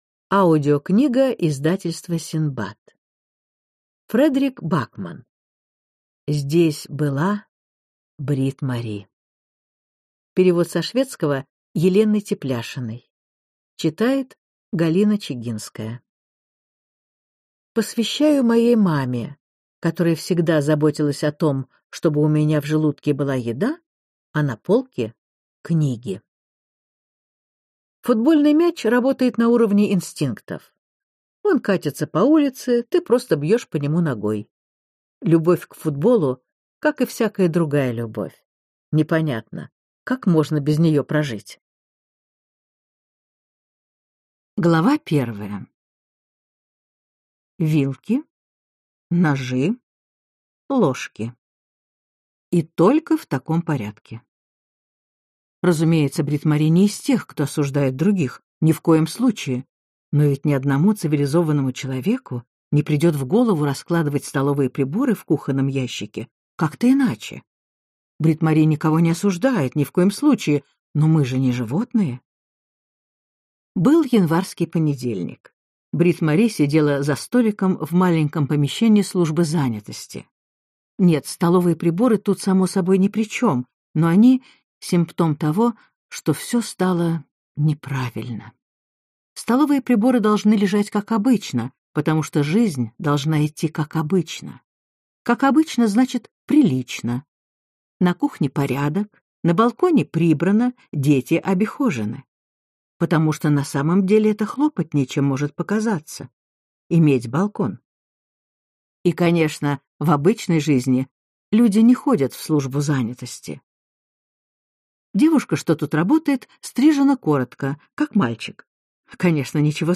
Аудиокнига Здесь была Бритт-Мари | Библиотека аудиокниг